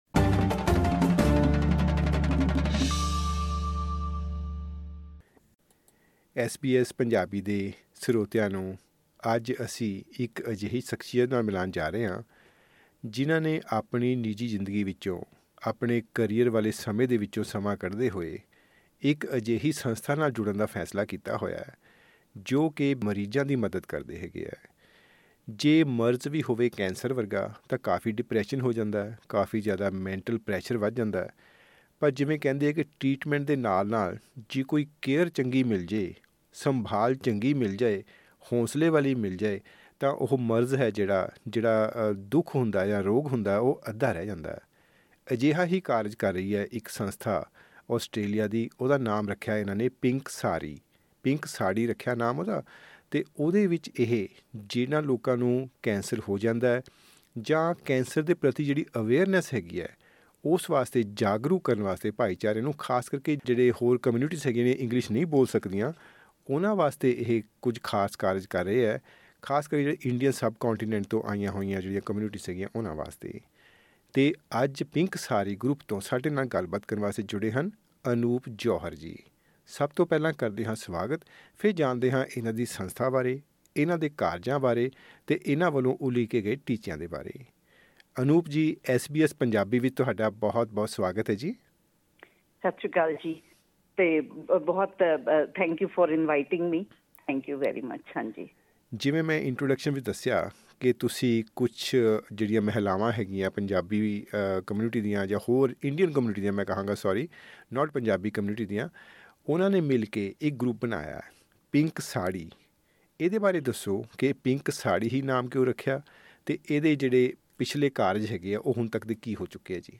ਗੱਲਬਾਤ